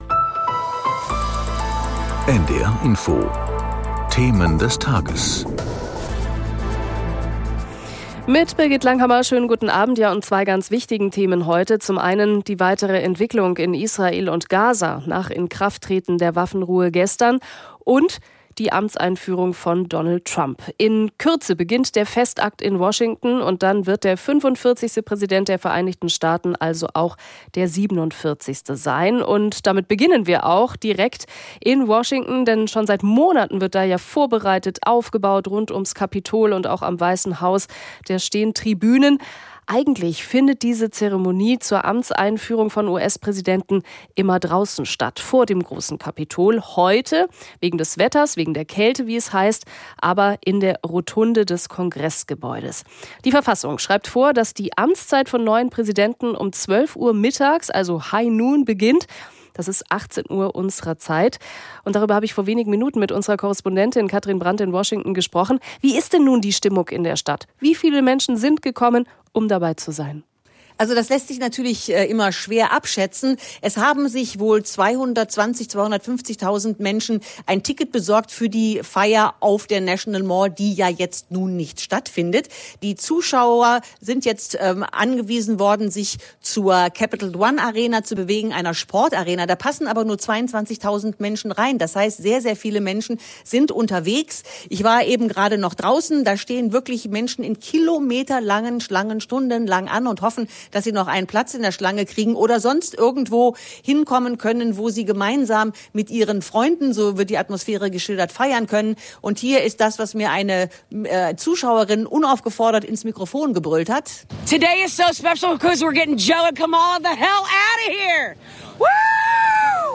In Gesprächen mit Korrespondenten und Interviews mit Experten oder Politikern.